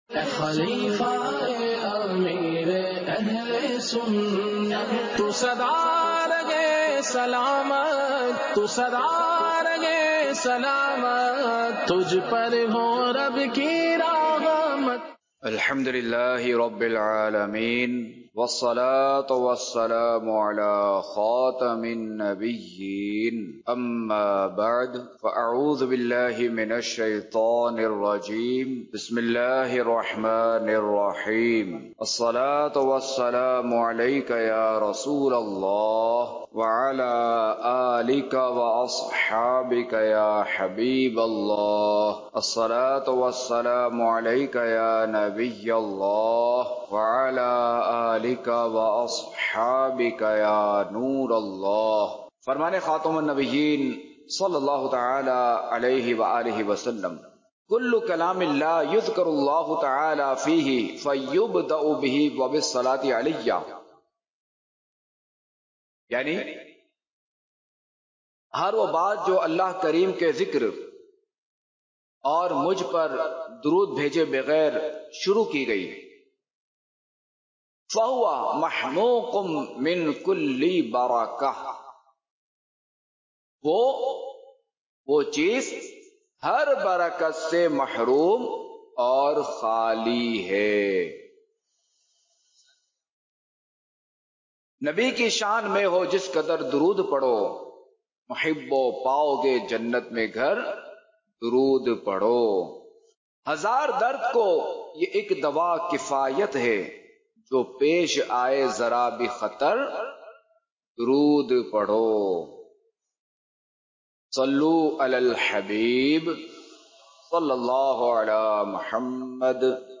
بیانات